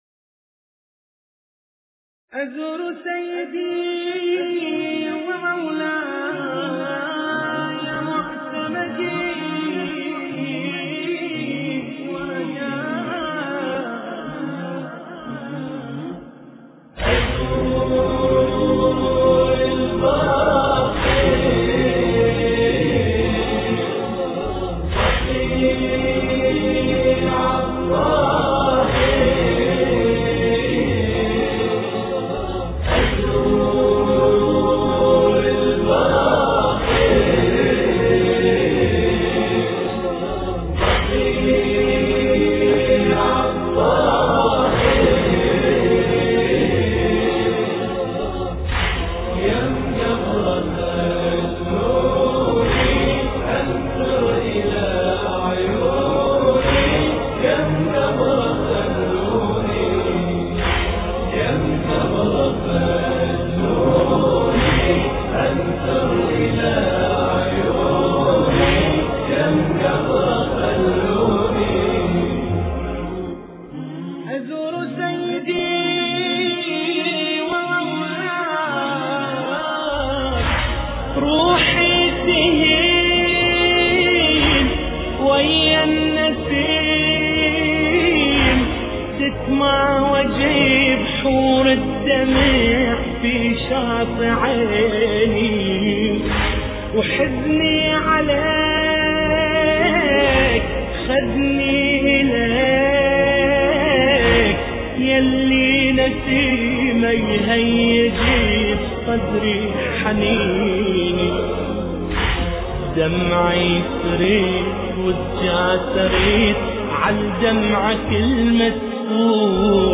مراثي الامام الباقر (ع)